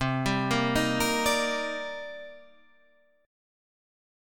Cm9 chord